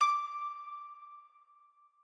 harp1_7.ogg